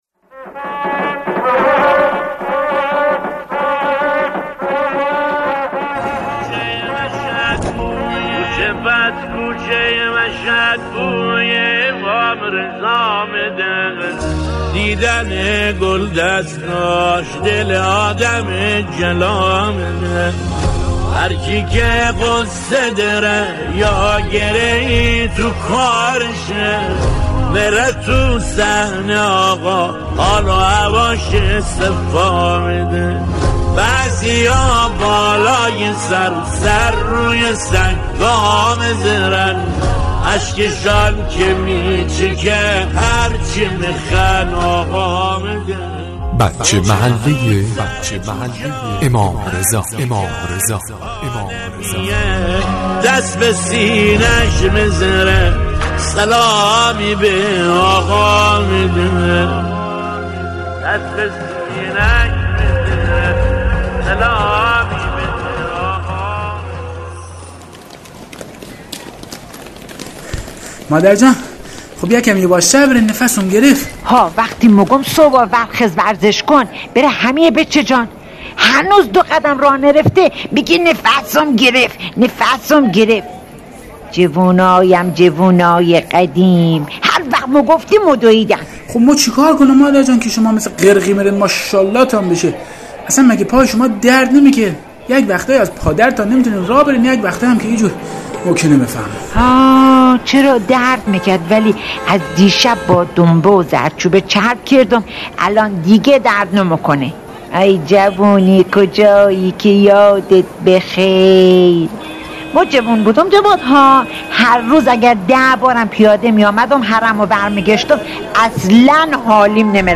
نمایش رادیویی جذاب از حال و هوای حرم در عید قربان